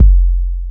MB Kick (41).wav